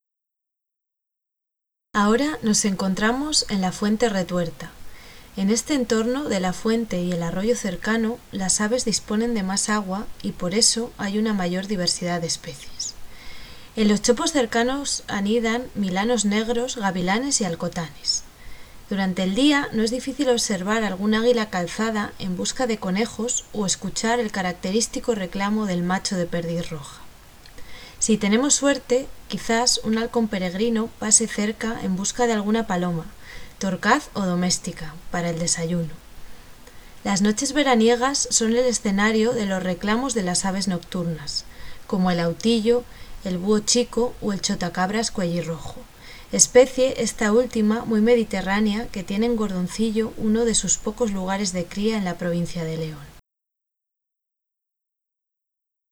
Audio descripción: